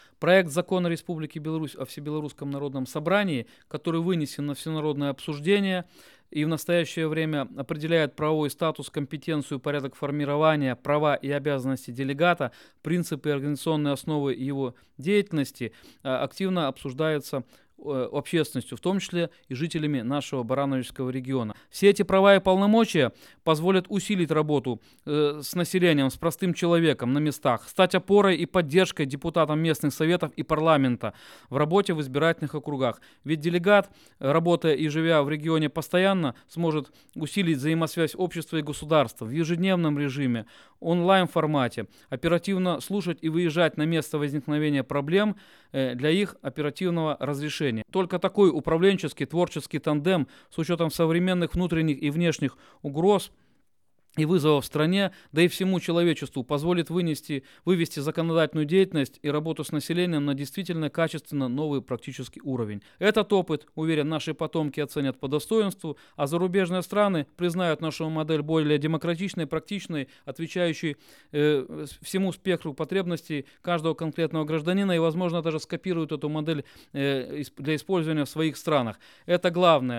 Главы местных Советов смогут представлять интересы граждан не только на областном, но и на республиканском уровнях, заявил депутат Палаты представителей Национального собрания Республики Беларусь Игорь Хлобукин. Народные избранники будут изучать общественное мнение, проводить встречи в трудовых коллективах и выносить предложения людей на рассмотрение ВНС.
hlobukin-na-830-sinhron-1.mp3